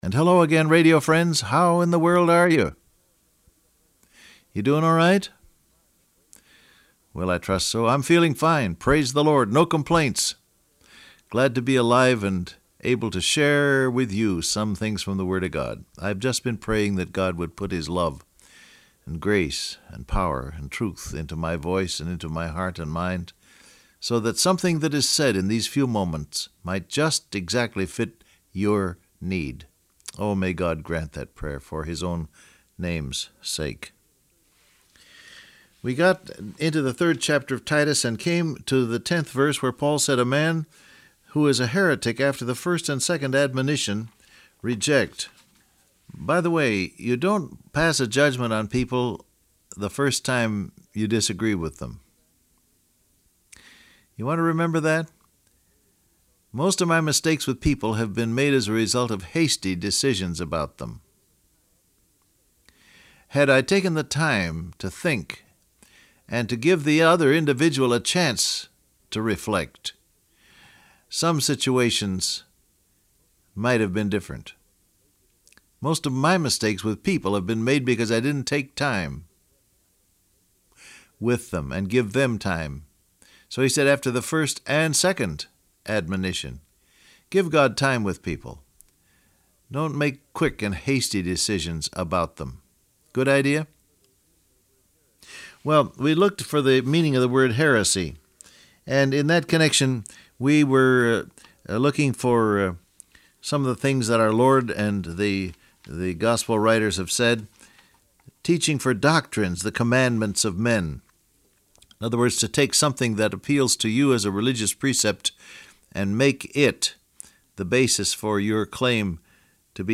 Download Audio Print Broadcast #6478 Scripture: Titus 3:10 , I Timothy, Colossians 3, 2 Peter 2 Topics: Hypocrisy , Conscience , Regulations Transcript Facebook Twitter WhatsApp And hello again radio friends.